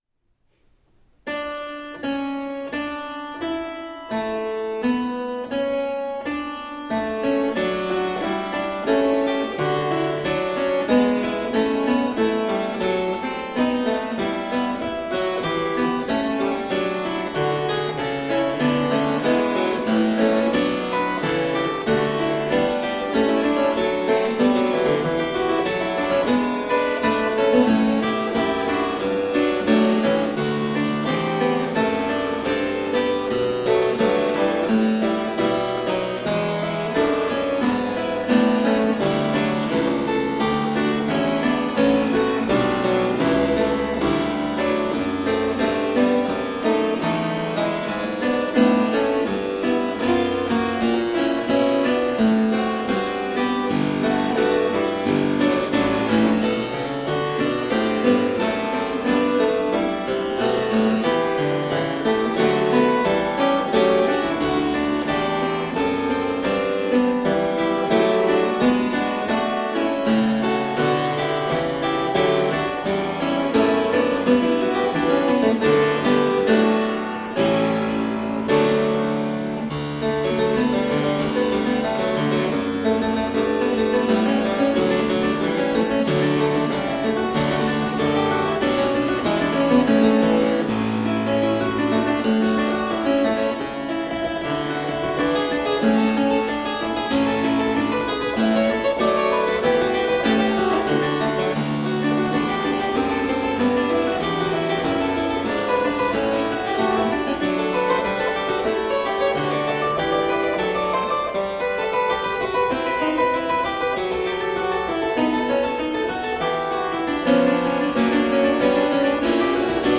Deeply elegant harpsichord.
Classical, Baroque, Renaissance, Instrumental
Harpsichord